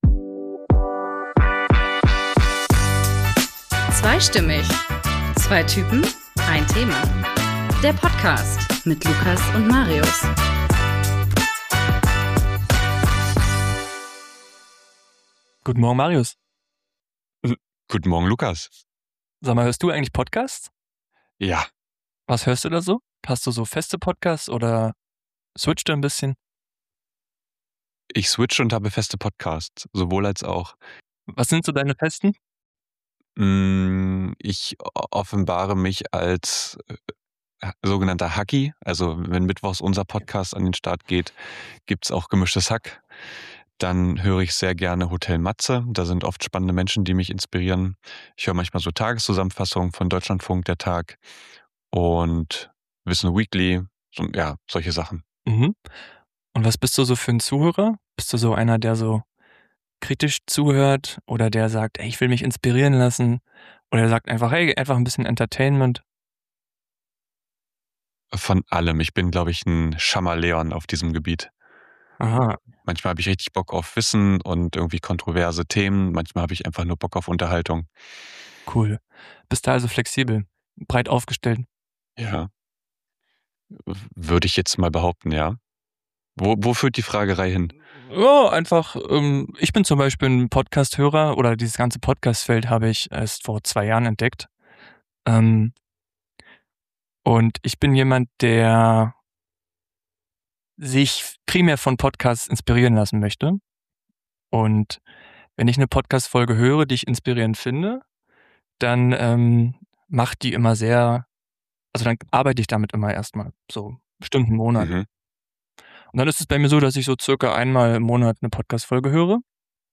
Seid dabei und hört, wie zwei Menschen sich selbst begegnen und einander Raum schenken.